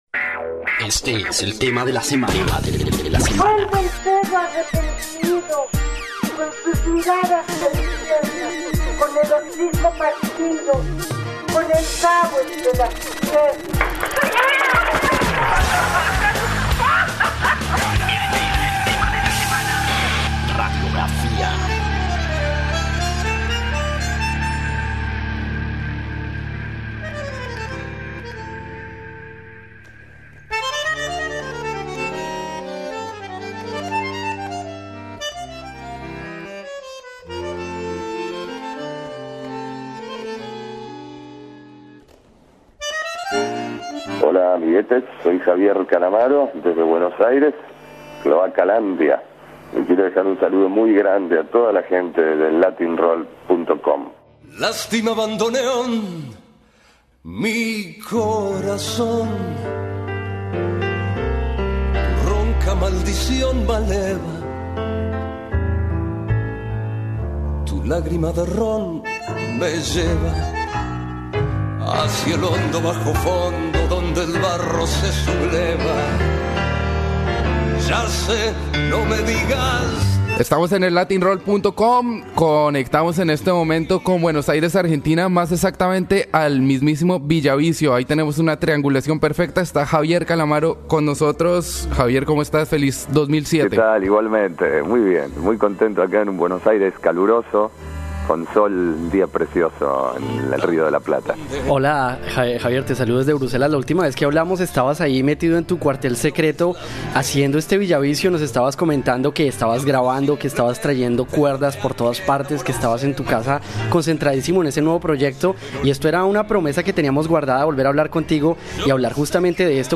Latin-Roll - Entrevistas Javier Calamaro Reproducir episodio Pausar episodio Mute/Unmute Episode Rebobinar 10 segundos 1x Fast Forward 30 seconds 00:00 / Suscribir Compartir Feed RSS Compartir Enlace Incrustar